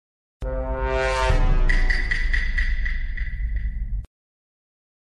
abstract-sound2.wav